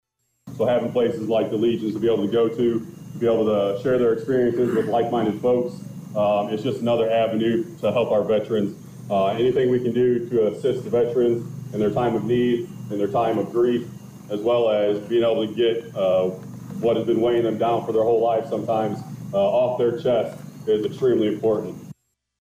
He told the crowd that American Legion posts play a vital role for veterans….